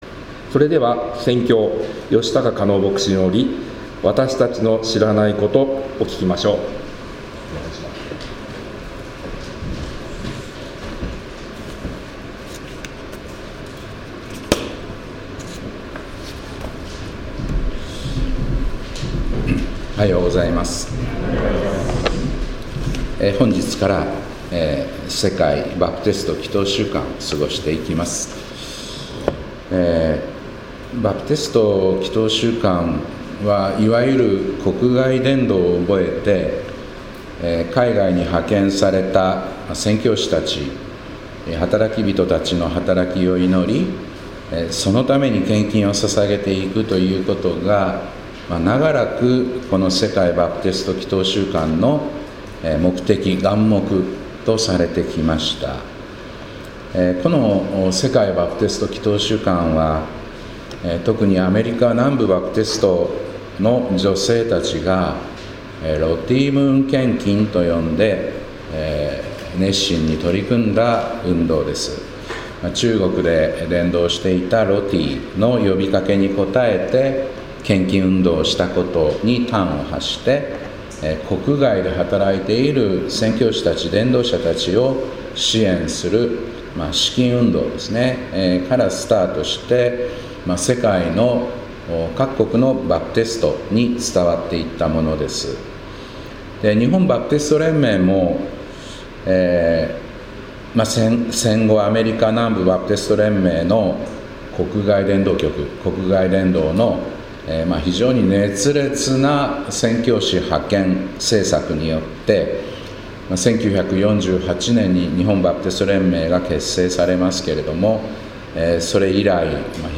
2024年11月24日礼拝「わたしたちの知らないこと」